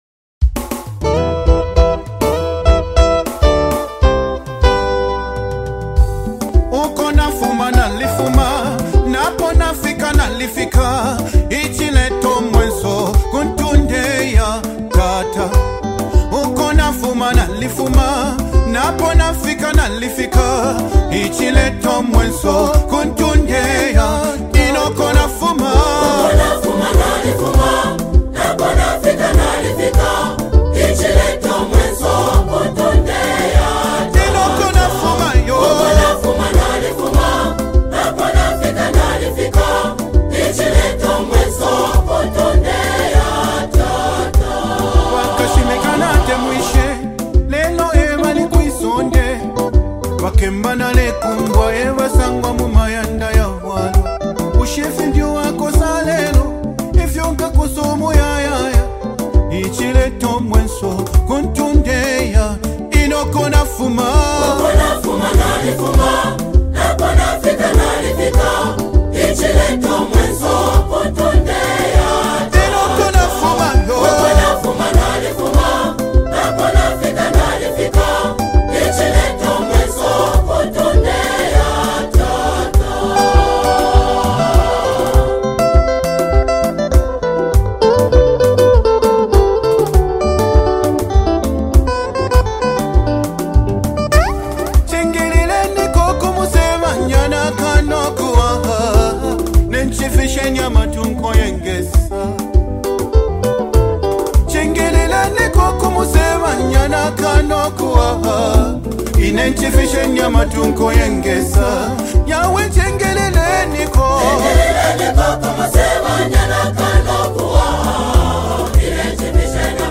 SPIRITUAL ENCOURAGEMENT HYMN | 2025 ZAMBIA GOSPEL
Known for their lyrical depth and passionate harmonies